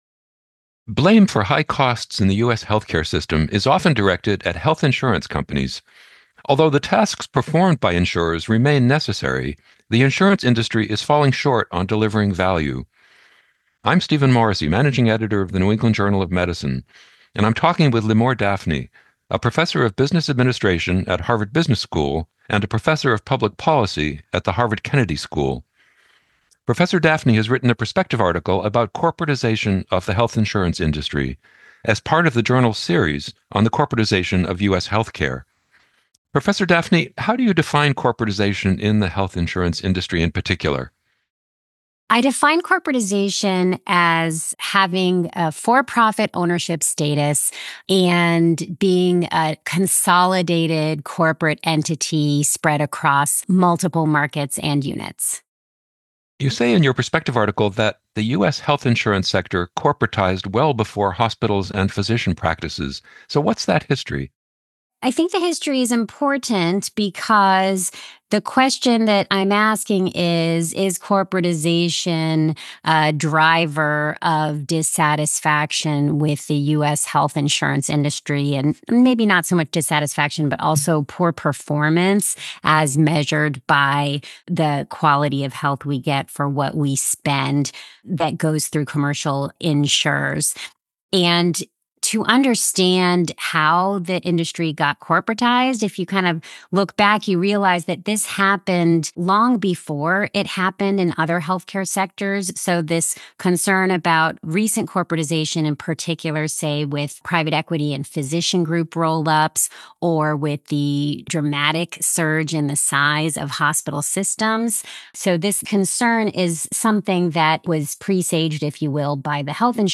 Insightful conversations with leading experts in the field of health care, medical research, policy, and more from the New England Journal of Medicine (NEJM). Each episode examines the many complexities found at the junction of medicine and society.